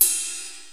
Cardiak Ride.WAV